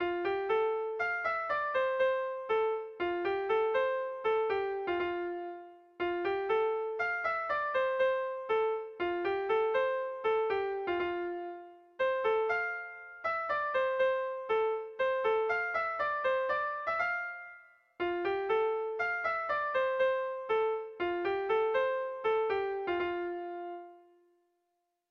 Air de bertsos - Voir fiche   Pour savoir plus sur cette section
Sentimenduzkoa
Zortziko ertaina (hg) / Lau puntuko ertaina (ip)
AABA